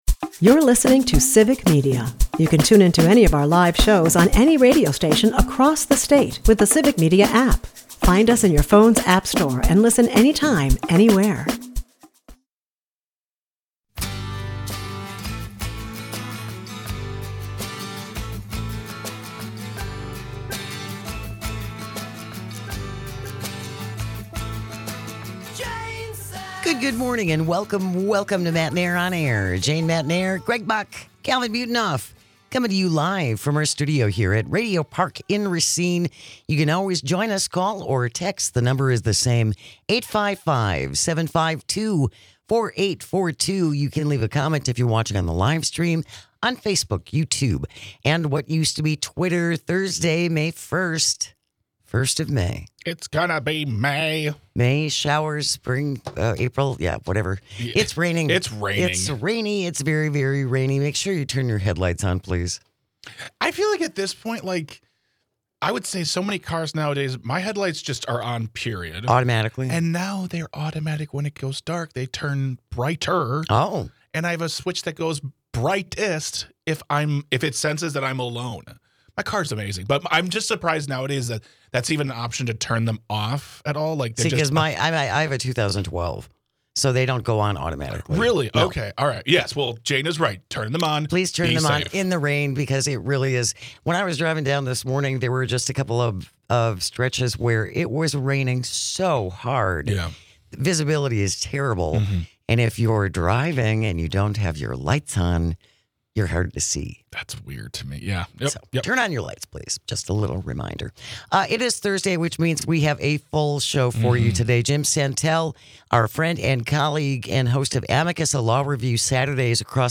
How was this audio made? Matenaer On Air is a part of the Civic Media radio network and airs Monday through Friday from 10 am - noon across the state.